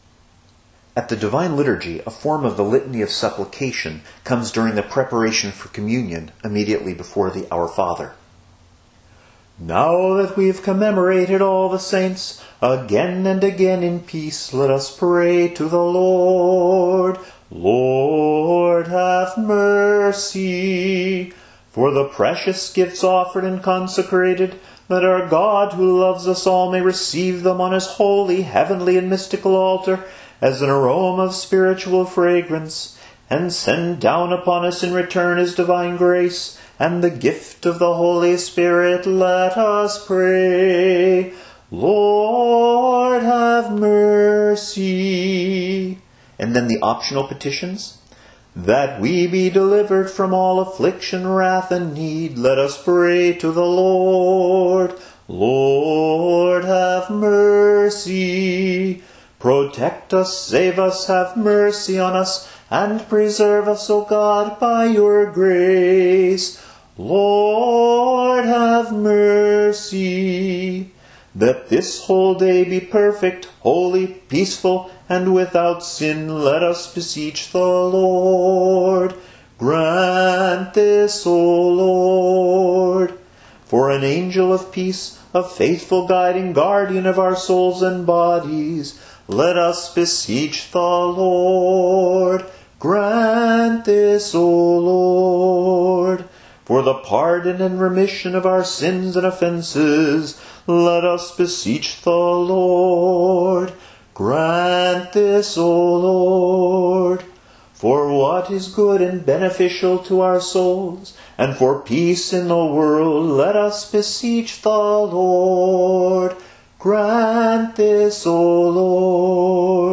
If he chooses to do so, he will usually end his petition with do - ti - la (that is, in a minor key):